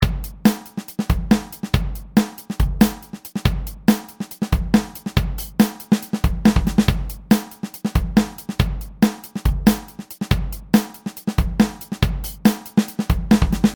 This is what I did for the fill at the end of my drum phrase, by putting slices with the snare drum overtop of each other.